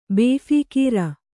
♪ bēphīkīra